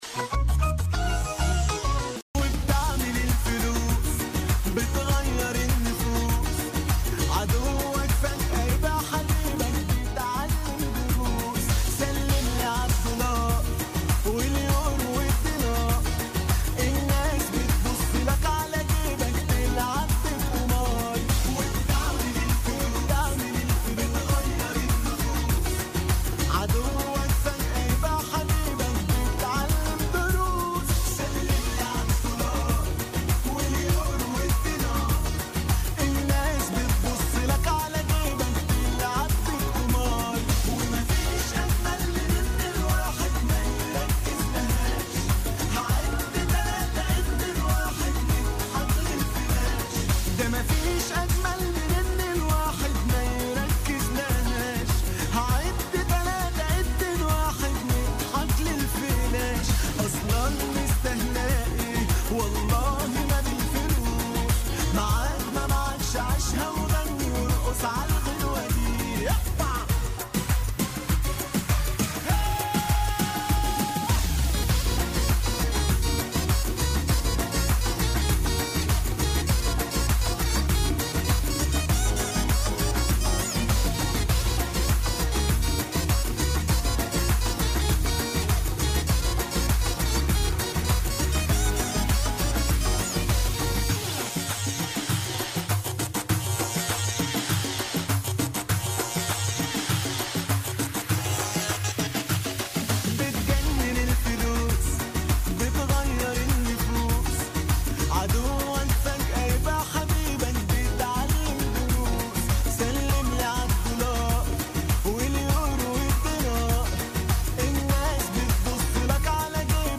وقالت ضيفة بوليتيكا إن زيارة رئيس الجمهورية بهذه المناسبة ستتزامن مع الإعلان عن مشاريع تنموية جديدة ونموذجية على مستوى ولاية المنستير ومنطقة الساحل بشكل عام بالاضافة إلى وضع حجر الأساس لدار المحامي التي ستحمل اسم الزعيم الحبيب بورقيبة.